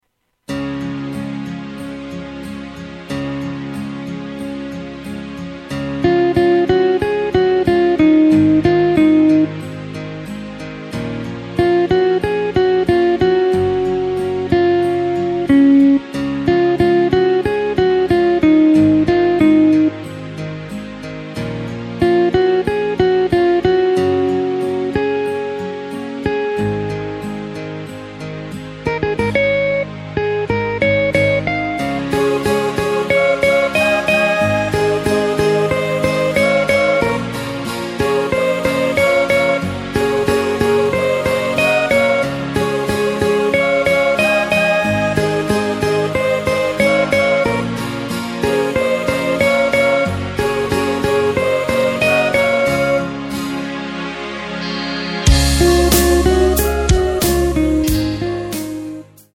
Takt:          4/4
Tempo:         92.00
Tonart:            C#
Austropop aus dem Jahr 2020!